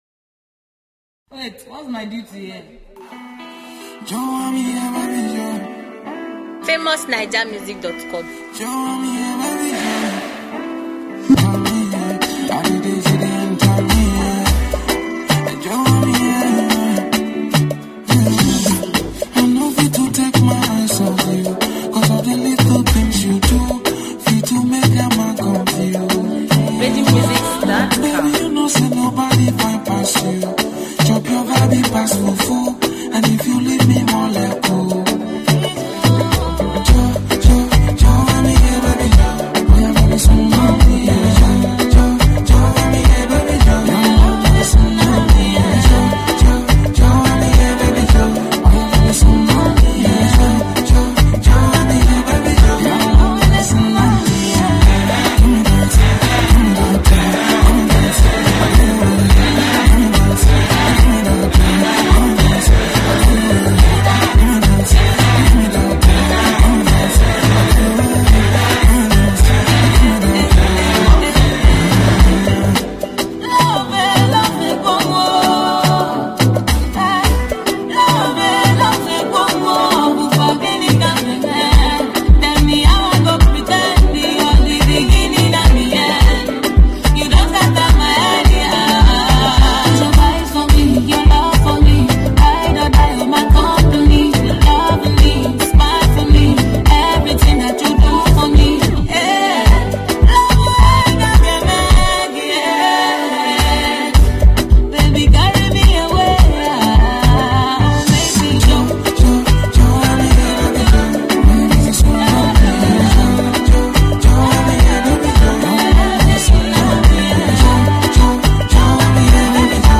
Nigerian female singer